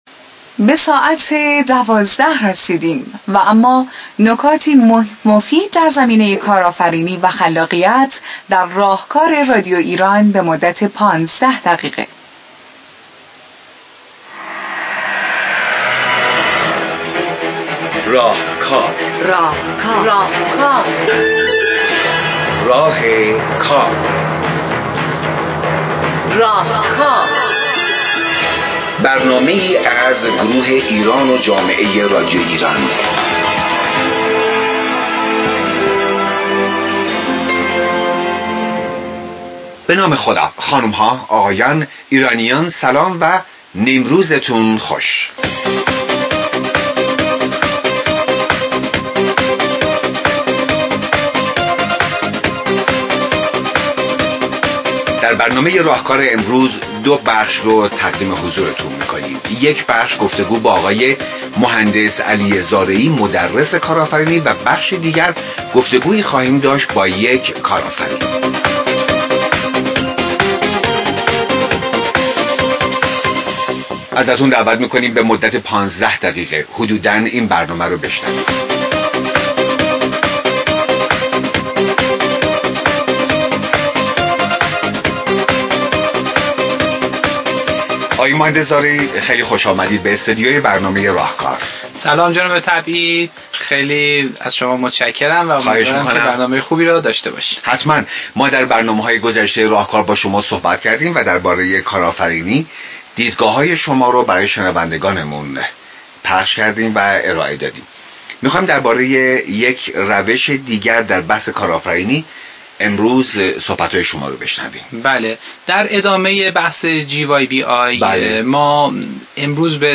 این برنامه در استودیو رادیو ایران ضبط و در خرداد 139 1 از صدای جمهوری اسلامی ایران - برنامه راهکار پخش گردیده است